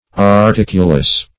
Search Result for " articulus" : The Collaborative International Dictionary of English v.0.48: Articulus \Ar*tic"u*lus\n.; pl.